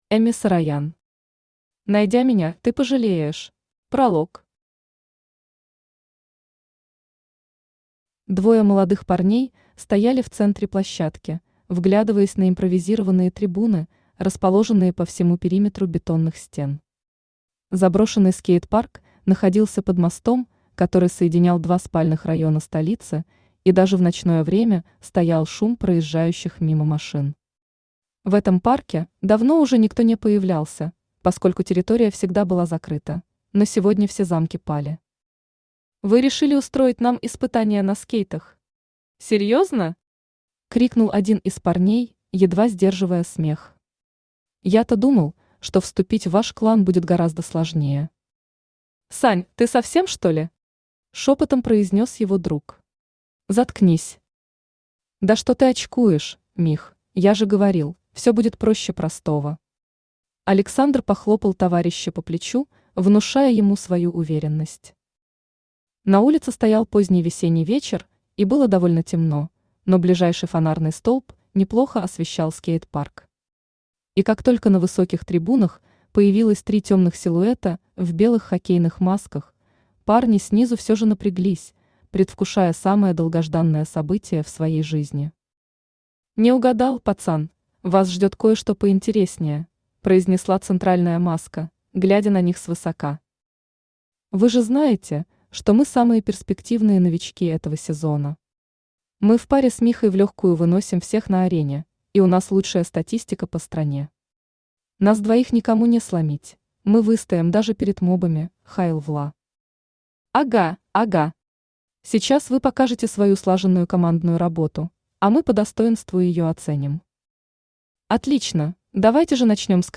Аудиокнига Найдя меня, ты пожалеешь | Библиотека аудиокниг
Aудиокнига Найдя меня, ты пожалеешь Автор Эми Сароян Читает аудиокнигу Авточтец ЛитРес.